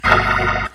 hallelujah organ Meme Sound Effect
hallelujah organ.mp3